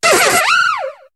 Cri de Rototaupe dans Pokémon HOME.